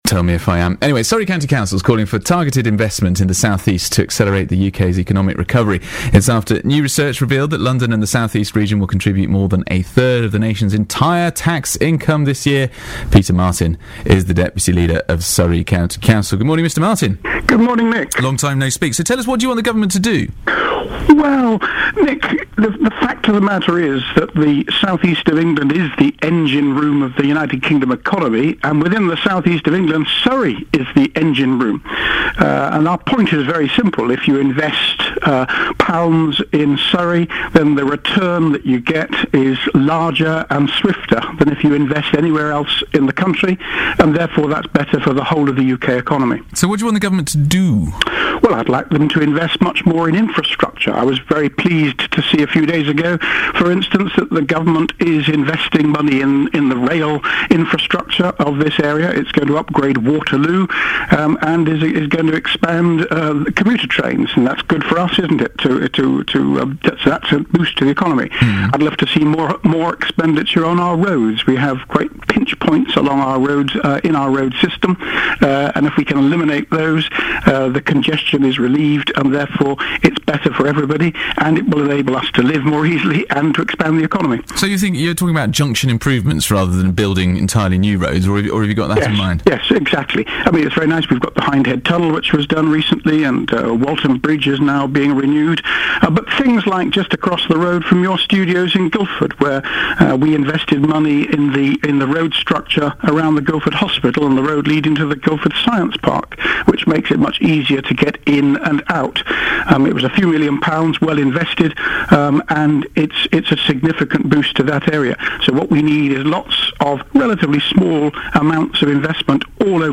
BBC radio interview – investing in Surrey
Peter Martin, deputy leader of Surrey County Council, was interviewed on BBC Surrey radio about the benefits of Government investment in Surrey and the rest of the South East.
The interview was broadcast on the morning of Saturday 12 January.